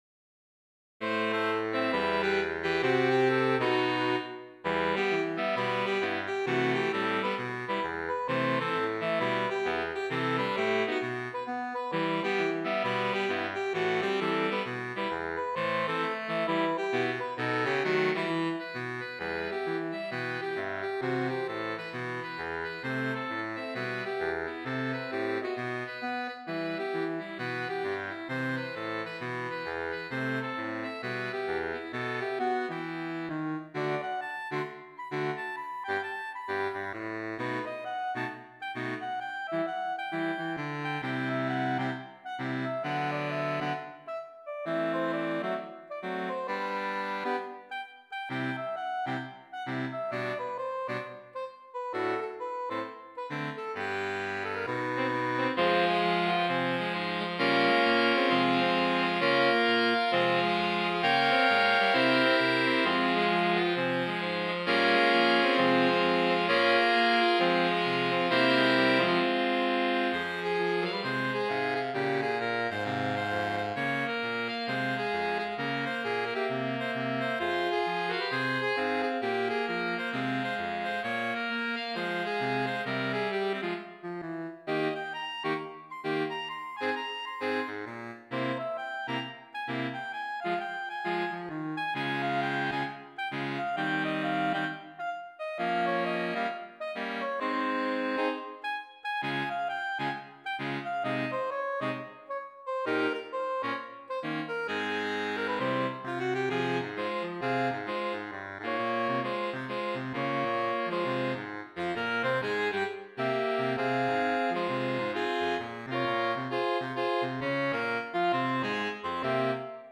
Voicing: Saxophone Quartet (SATB)